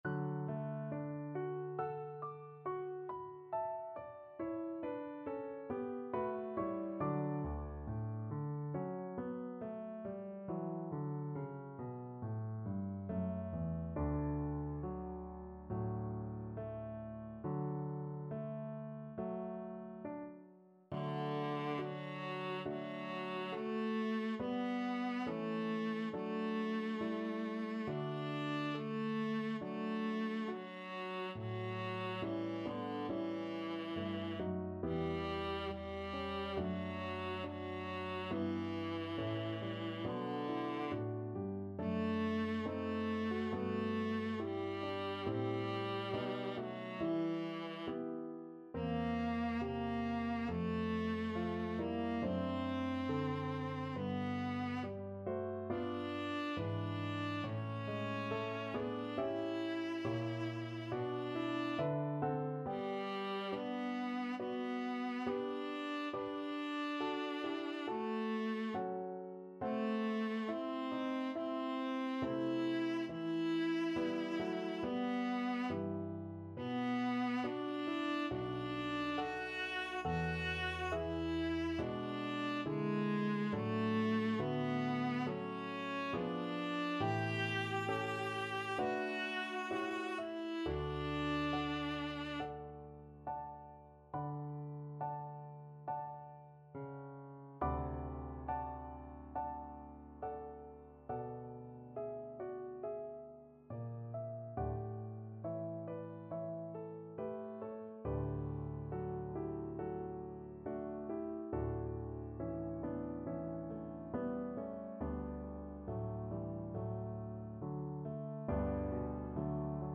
D major (Sounding Pitch) (View more D major Music for Viola )
Slow =c.69
Viola  (View more Easy Viola Music)
Classical (View more Classical Viola Music)